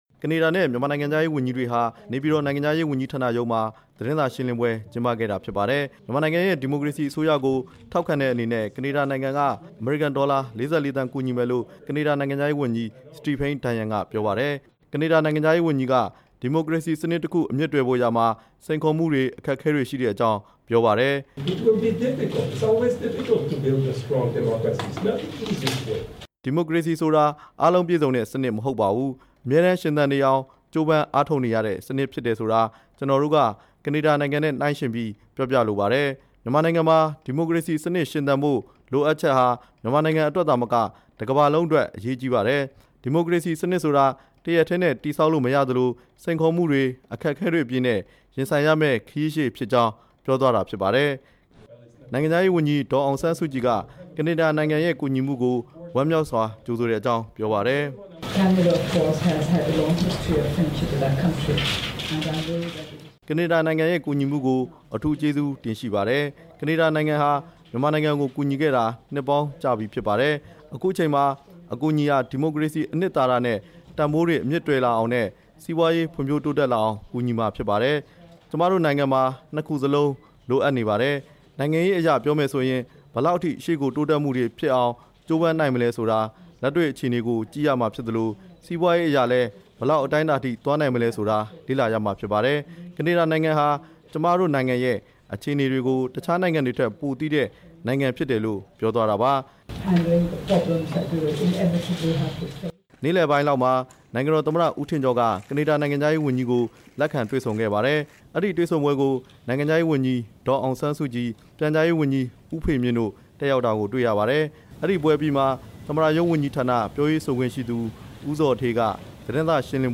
ကနေဒါနိုင်ငံခြားရေးဝန်ကြီးနဲ့ ဒေါ်အောင်ဆန်းစုကြည် ပူးတွဲသတင်းစာရှင်းလင်း